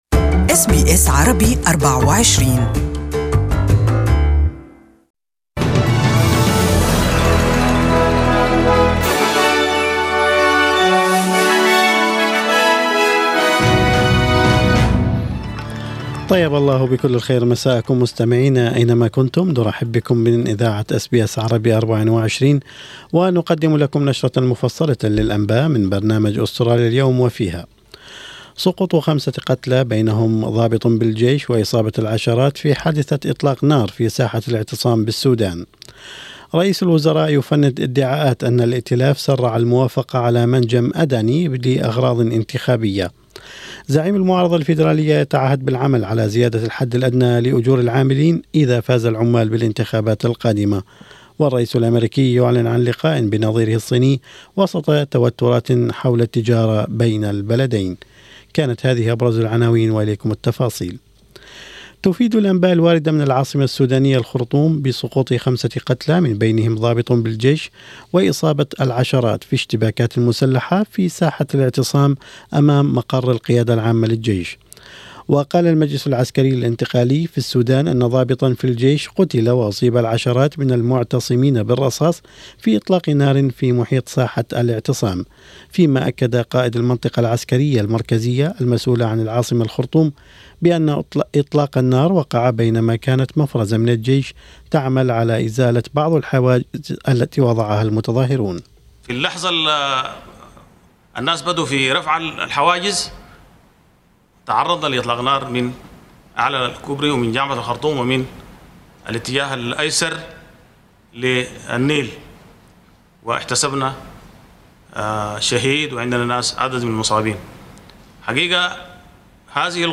Evening News Bulletin: Labor vows to raise Australian wages on ‘first day of government’